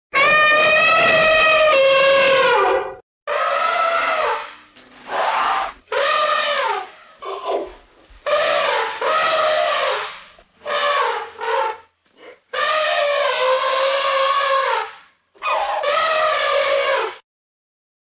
دانلود آهنگ فیل 3 از افکت صوتی انسان و موجودات زنده
دانلود صدای فیل 3 از ساعد نیوز با لینک مستقیم و کیفیت بالا
جلوه های صوتی